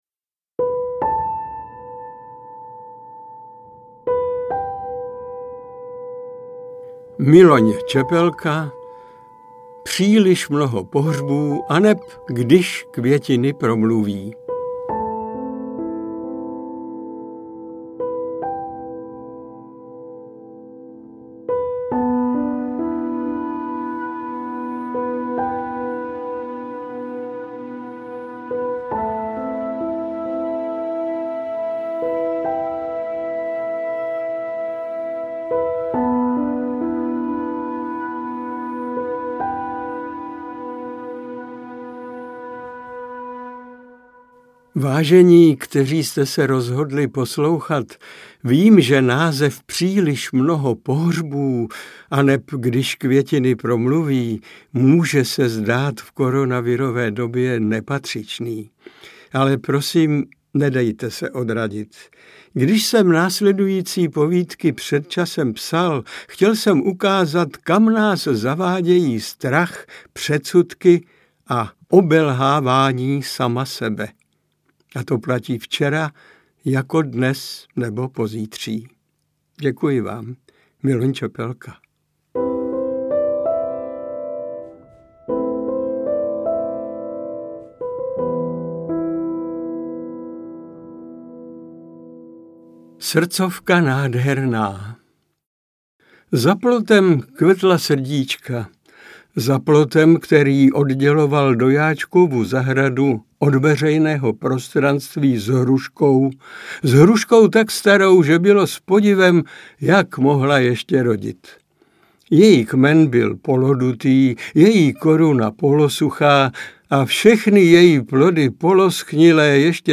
Interpret:  Miloň Čepelka